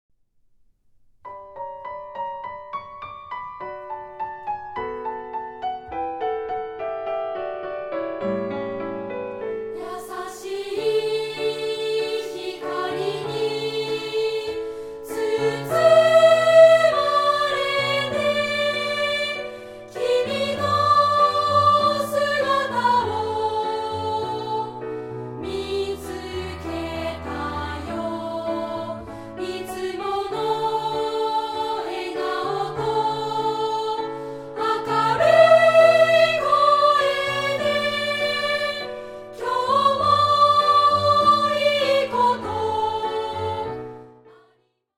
2部合唱／伴奏：ピアノ